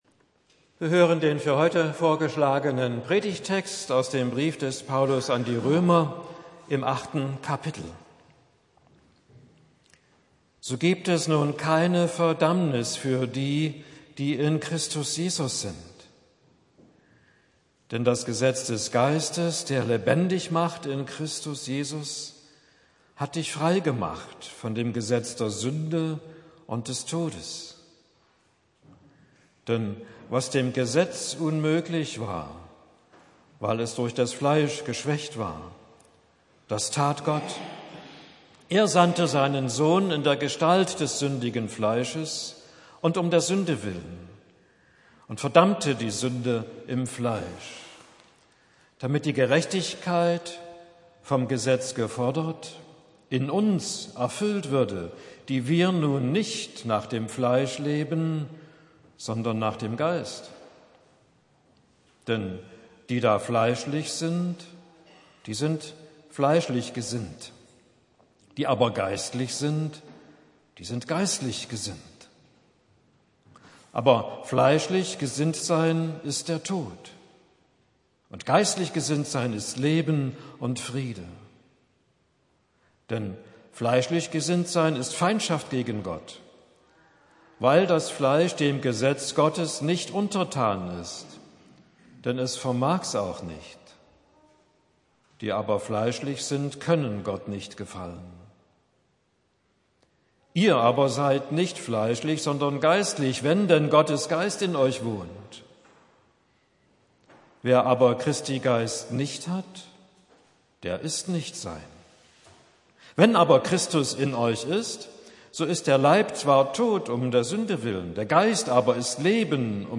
Auch möglich zur Jubelkonfirmation Johannes 07,37-39 Weiterlesen » Römer 08,1-11 Predigt für Pfingsten Sie können das Manuskript HIER NACHLESEN!